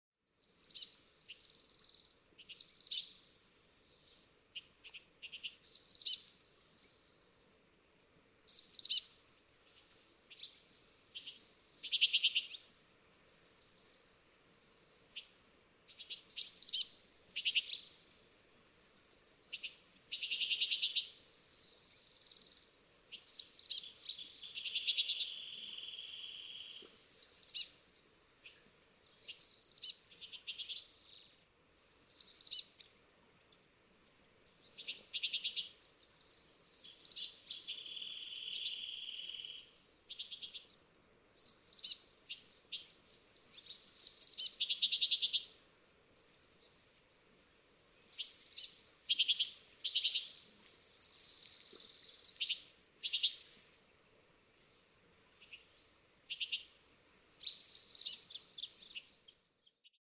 Chestnut-backed chickadee
♫454. Example 2, with calling Bewick's wren and singing orange-crowned warbler, Wilson's warbler.
Montaña de Oro State Park, Los Osos, California.
454_Chestnut-backed_Chickadee.mp3